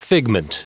Prononciation du mot figment en anglais (fichier audio)
Prononciation du mot : figment